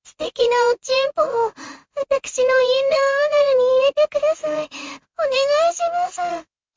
Canary-TTS-0.5Bをつまんでみたで
プロンプトで声を指定できるんでヤンデレとツンデレの声をChatGPTに説明してもろて生成してもろた
CanaryTts_0_Tundere.mp3